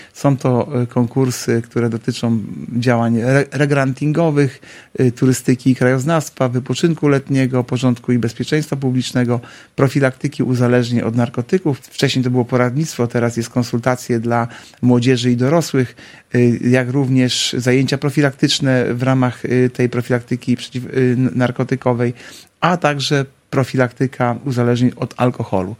– Realizowanych będzie osiem przedsięwzięć – mówi Artur Urbański, zastępca prezydenta Ełku.